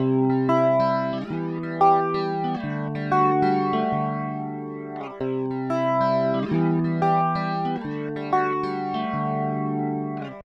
4. Flanger
Flanger es un efecto que consiste en la duplicación de la señal y en una de ellas se le aplica un retraso de menos 5ms y algunos filtros.
Es un efecto bastante particular y extraño, pero que queda encaja bien en algunas canciones.
efecto-de-flanger_vriogh.ogg